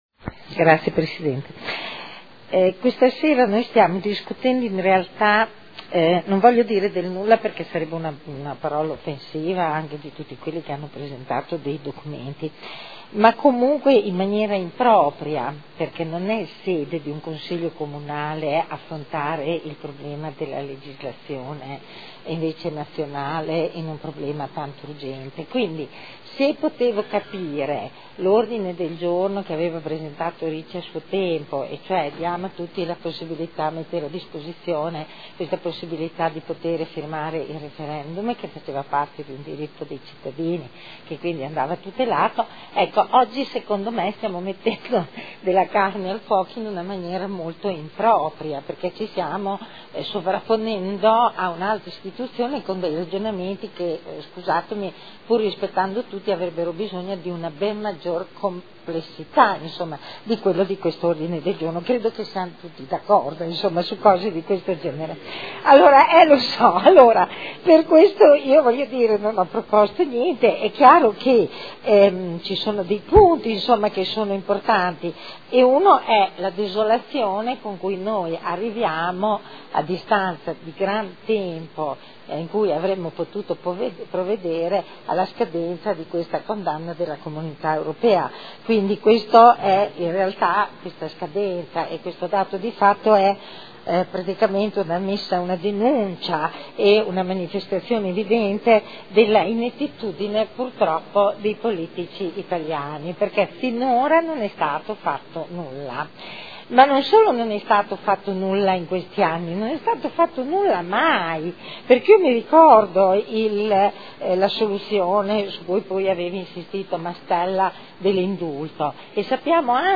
Seduta del 24/10/2013. Dibattito sugli ordini del giorno e sull'emendamento presentati dai consiglieri Barcaiuolo, Ricci, Cavani e Trande